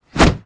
zombi_swing_1.wav